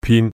Yue-pin3.mp3